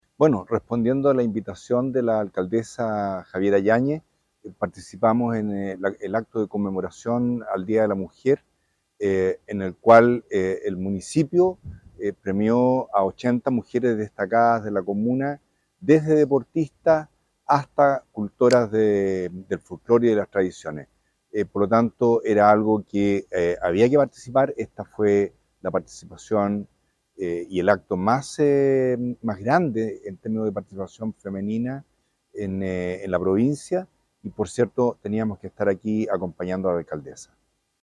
Esto se realizó en una emotiva y concurrida ceremonia la tarde de este viernes 8 de marzo en el gimnasio de la población Bordemar, la cual fue en encabezada por la alcaldesa Javiera Yáñez, junto al Concejo Municipal; la presencia del Delegado Presidencial de la Provincia de Chiloé, Marcelo Malagueño; el Consejero Regional Nelson Águila e invitados especiales y familiares de las mujeres que fueron destacadas este 2024.
Por su parte, el representante del Gobierno en el archipiélago, manifestó:
DELEGADO-8M-.mp3